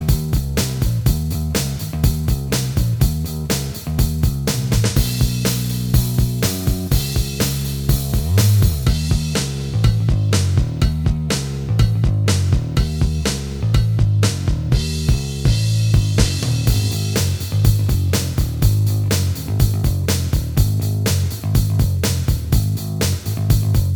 Minus Guitars Rock 4:24 Buy £1.50